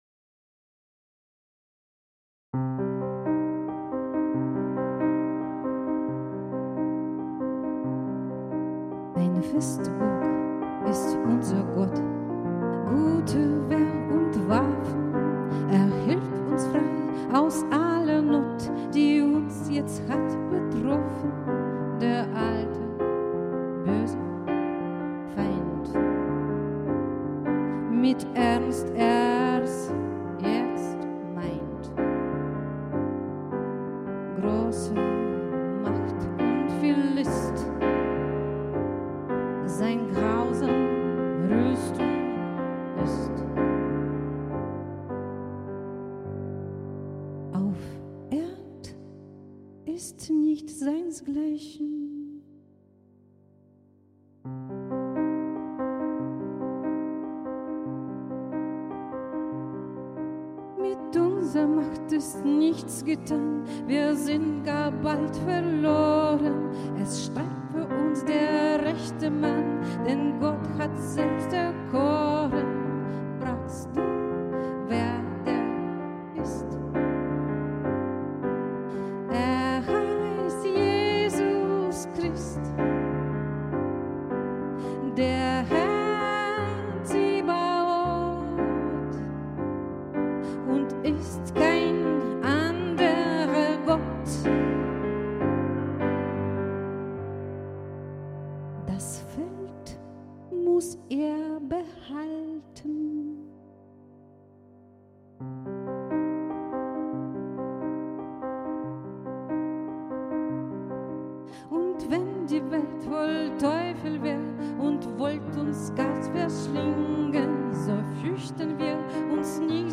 Täglich veröffentlicht die Landeskirche Anhalts als Video oder Audio ein geistliches Musikstück mit Musikerinnen und Musikern aus Anhalt sowie Informationen dazu und der jeweiligen Tageslosung mit Lehrtext.